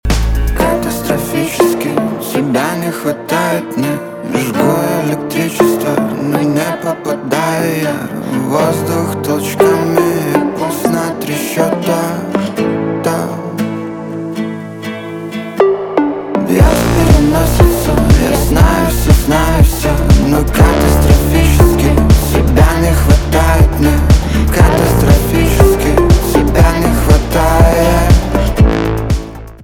поп
гитара
грустные